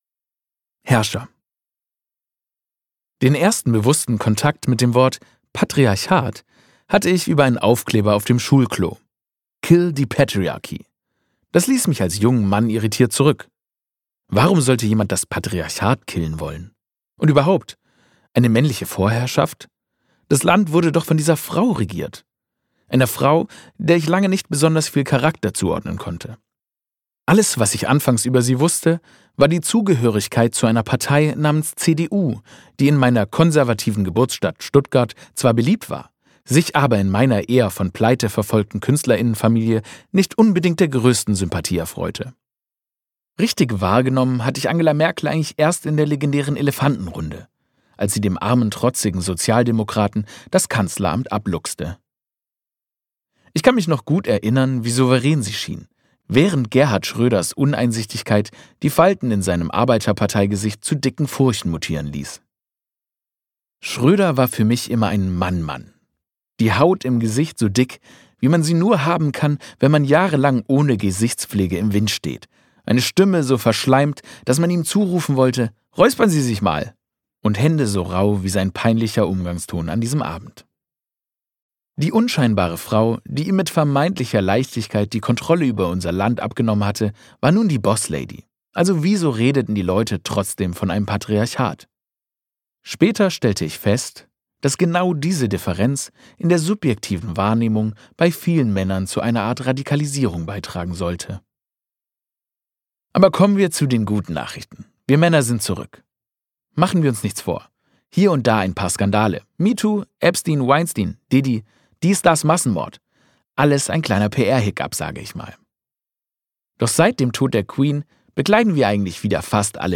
Alpha-Boys Gelesen von: Aurel Mertz
• Sprecher:innen: Aurel Mertz